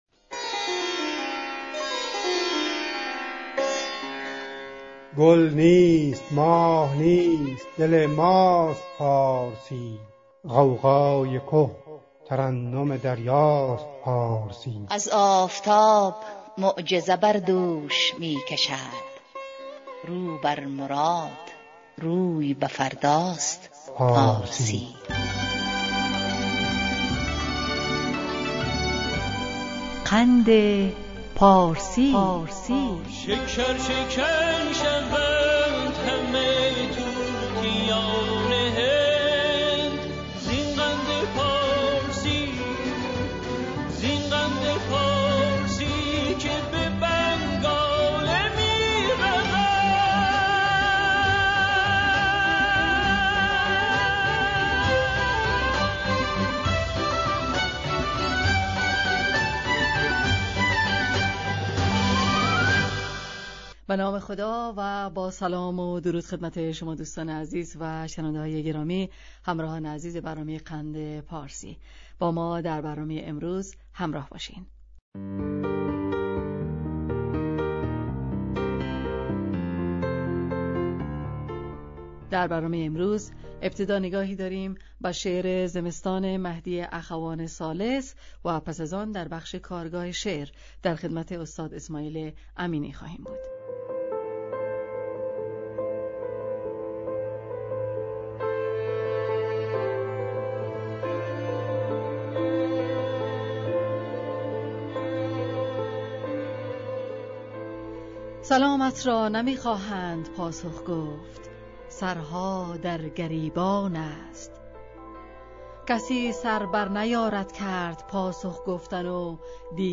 مهدی اخوان ثالث برنامهٔ رادیویی پخش‌شده از رادیو دری، در ۲۴ شهریور ۱۳۹۹ https